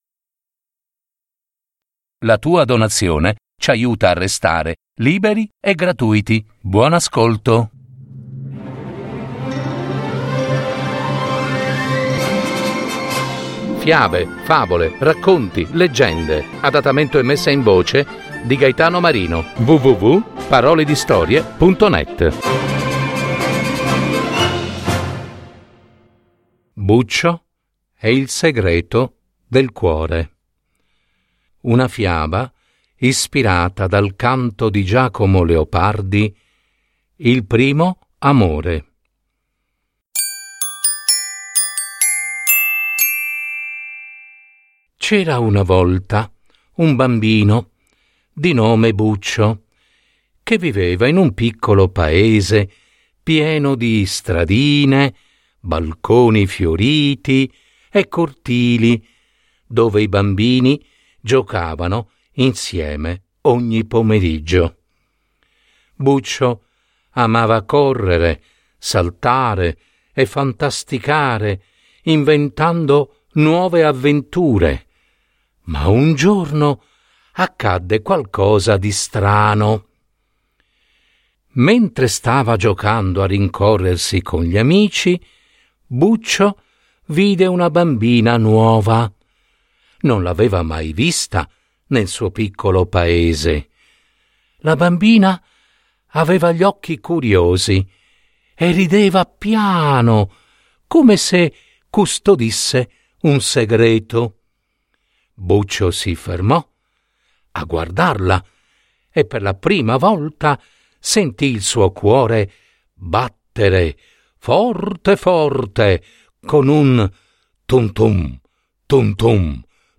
Buccio e il segreto del cuore. Una fiaba ispirata dai Canti di Giacomo Leopardi